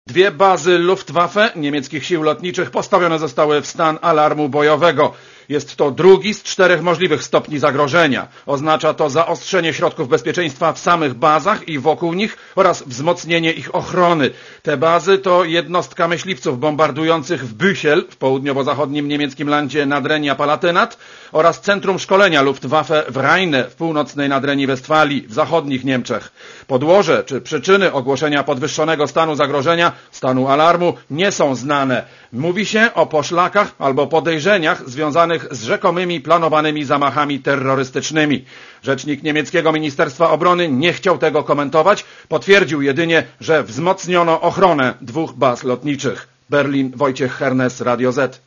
Posłuchaj korespondencji z Berlina